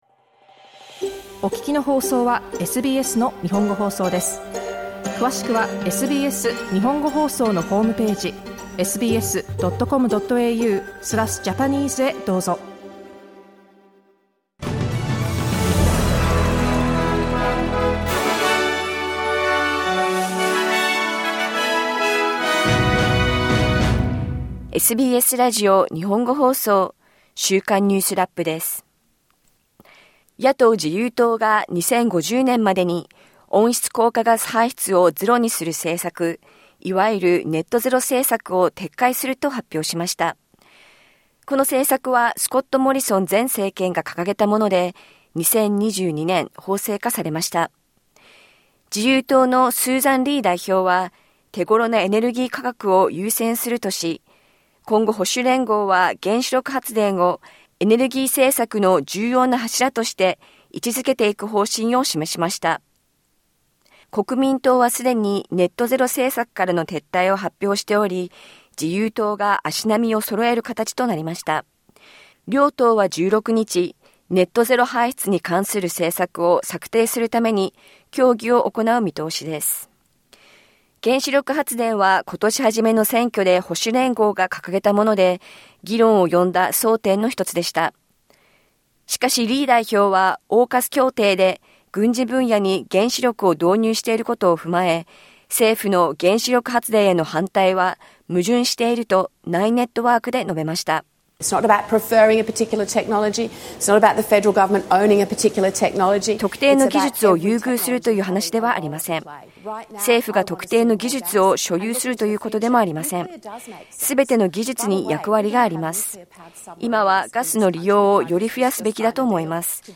アメリカ史上最長となった政府機関の一部閉鎖が解消される見通しとなりました。1週間を振り返るニュースラップです。